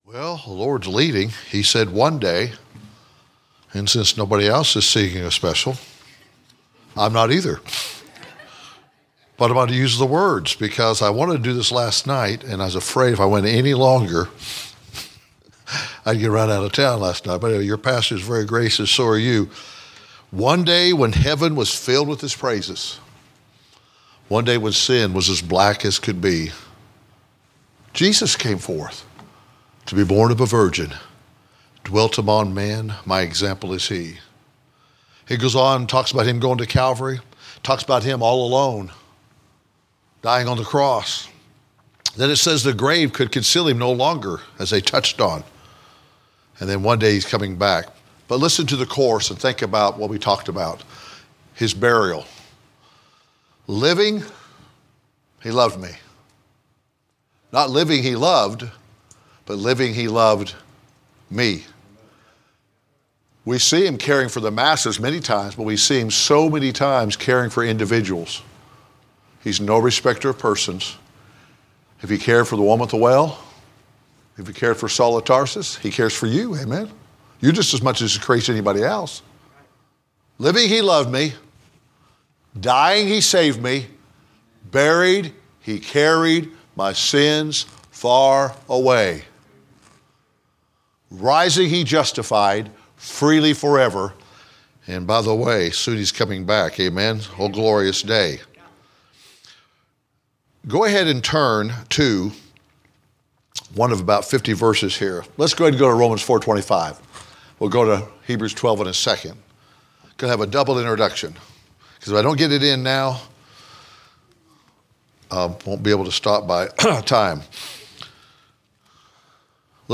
A message from the series "General Preaching."